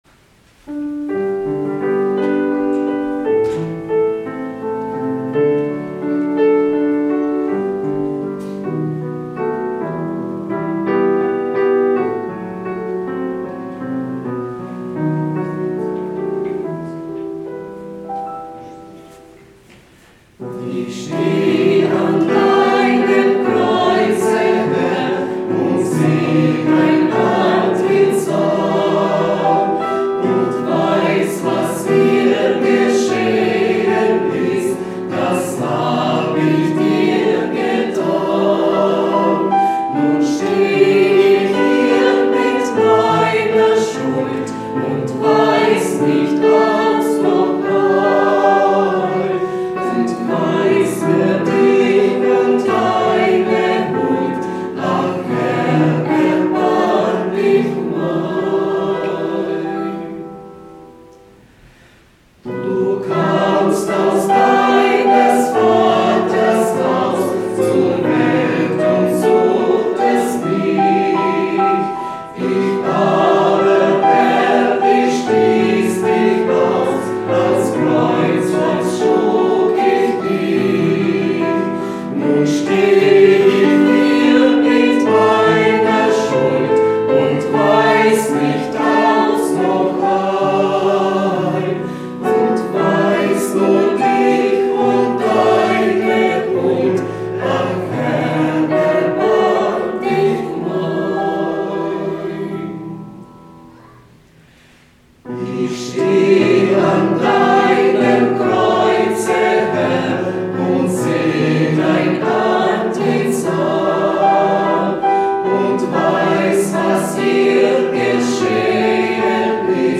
Chor